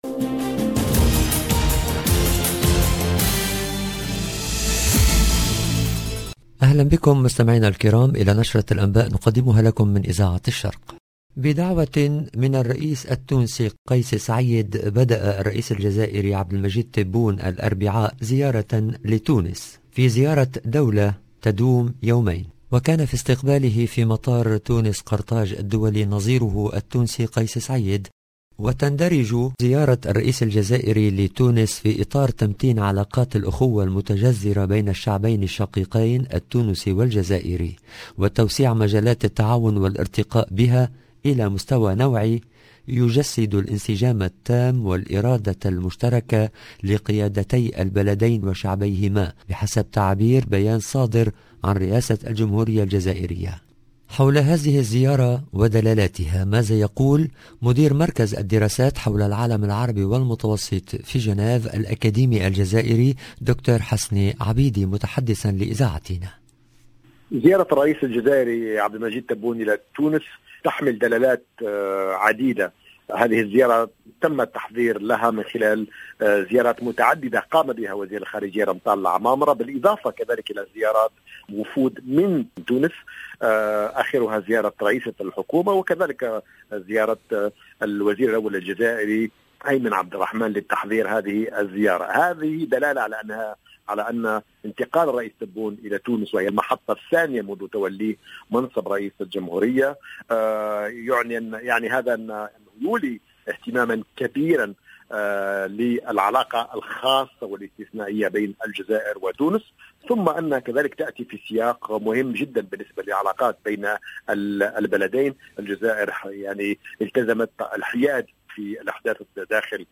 LB JOURNAL EN LANGUE ARABE